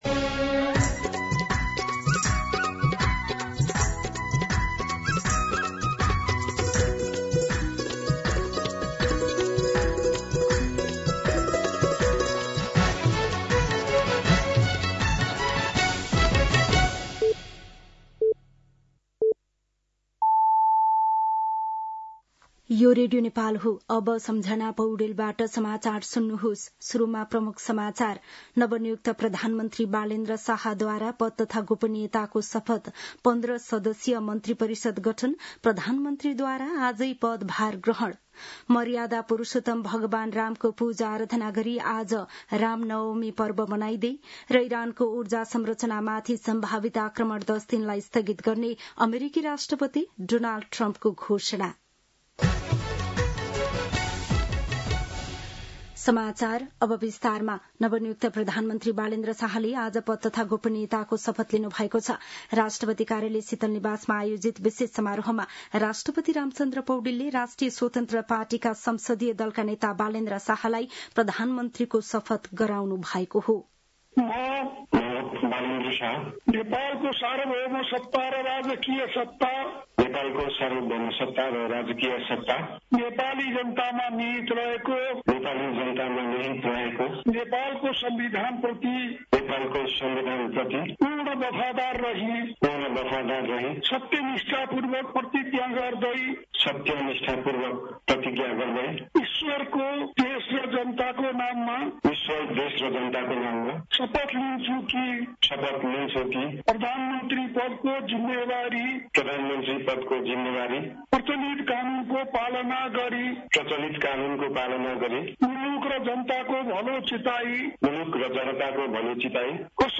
दिउँसो ३ बजेको नेपाली समाचार : १३ चैत , २०८२
3-pm-Nepali-News-4.mp3